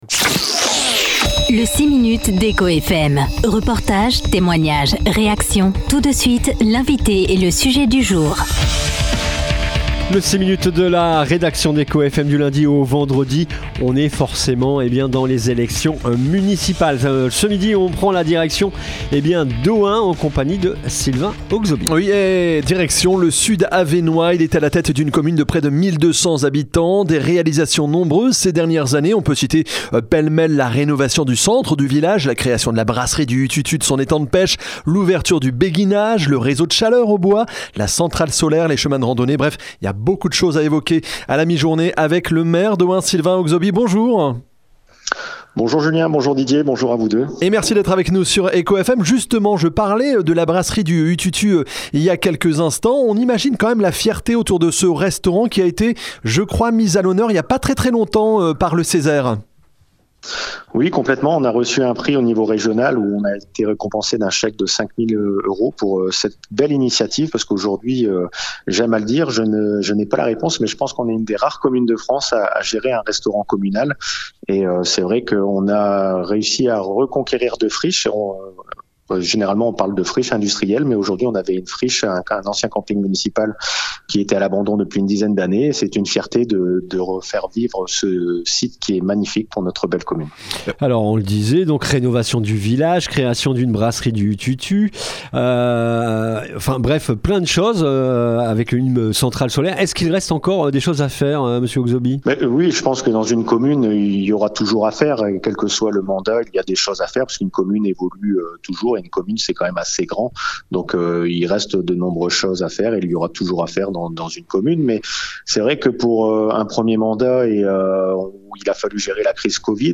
Invité du “6 minutes d’Écho FM” ce jeudi 13 novembre, Sylvain Oxoby, maire d’Ohain, a fait le point sur un premier mandat particulièrement dense. Dans cette commune de moins de 1 200 habitants, les projets se sont enchaînés à un rythme soutenu : rénovation du centre du village, ouverture d’un béguinage, centrale solaire, restaurant communal mis à l’honneur par le CESER récemment...